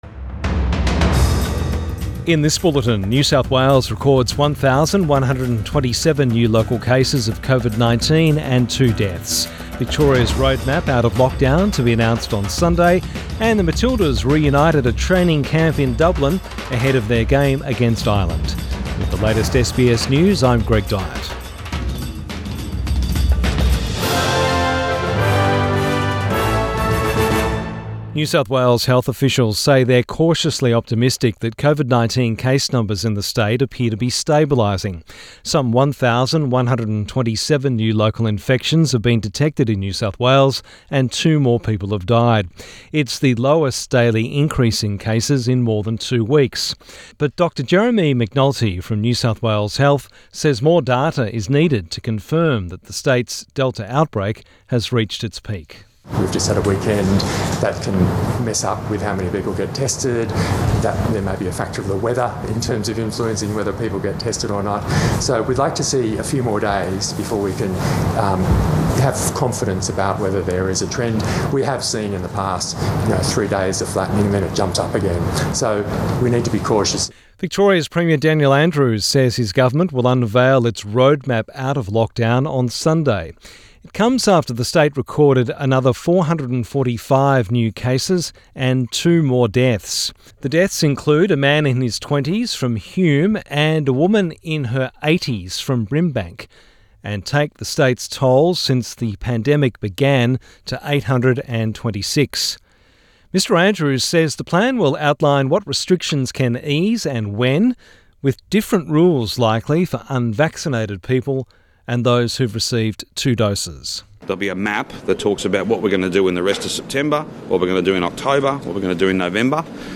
PM bulletin 14 September 2021